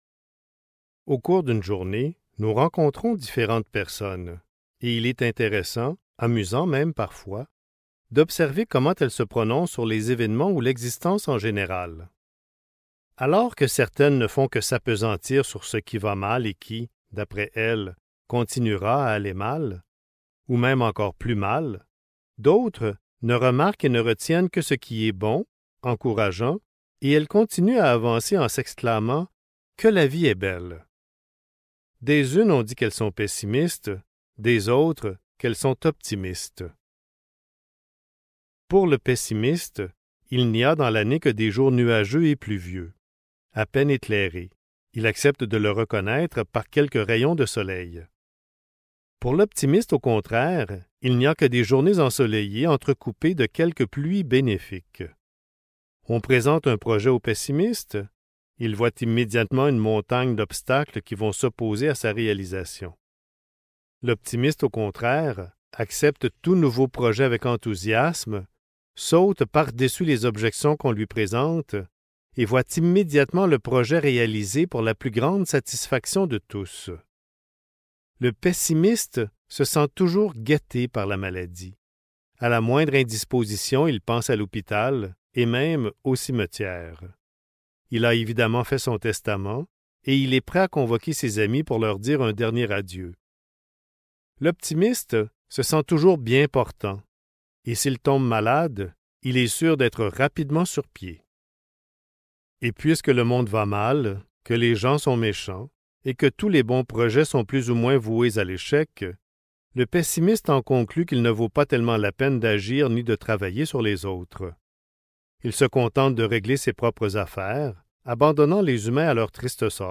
Le rire du sage (Livre audio | Téléchargement) | Omraam Mikhaël Aïvanhov